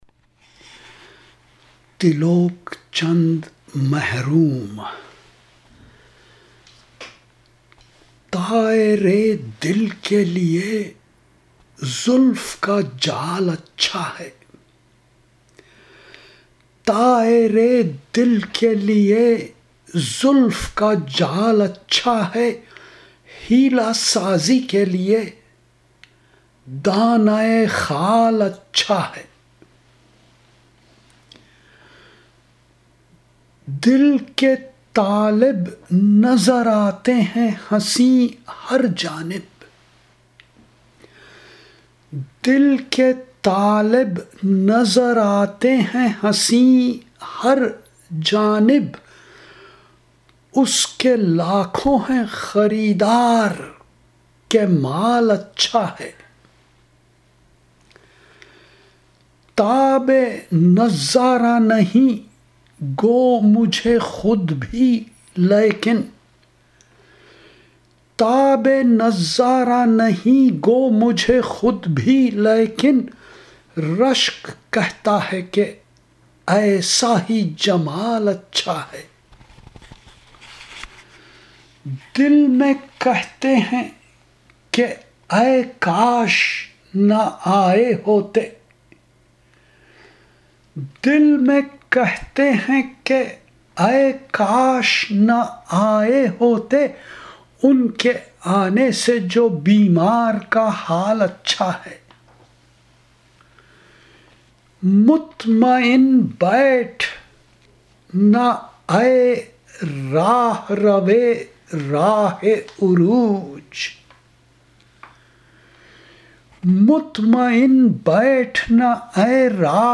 Recitation